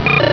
Cri de Héricendre dans Pokémon Rubis et Saphir.